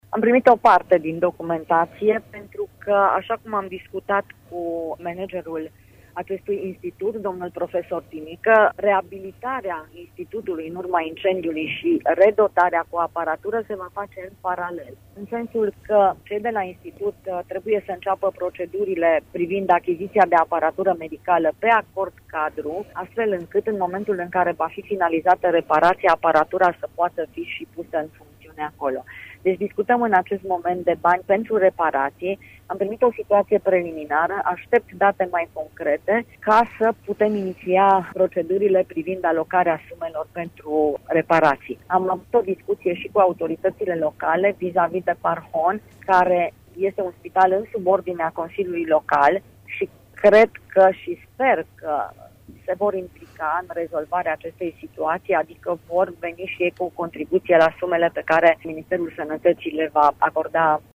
Ministrul sănătății, Sorina Pintea, a mai declarat, la Radio Iași, în emisiunea Imperativ, că a primit o parte din documentația necesară eliberării sumelor pentru Institutul de Boli Cardiovasculare, afectat de incendiu în urmă cu câteva săptămâni, dar că aceasta nu este completă, încă.